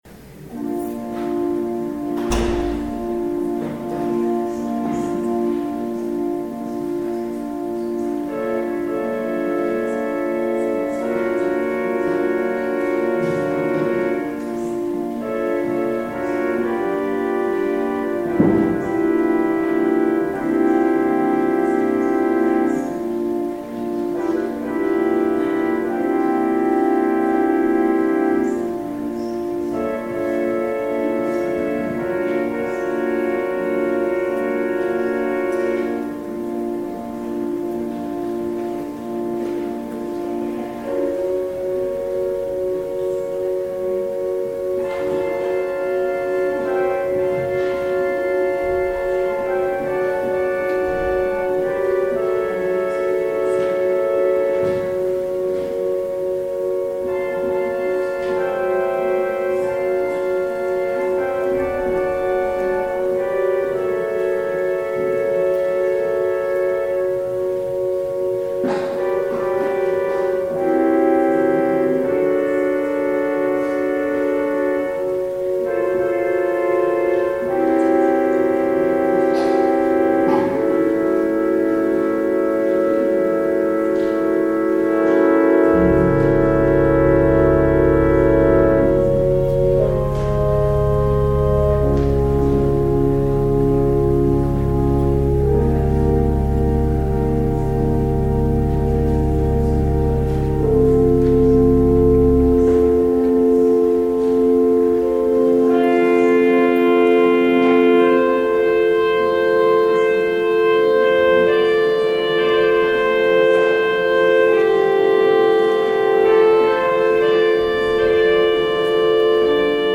Audio recording of the 10am service, sermon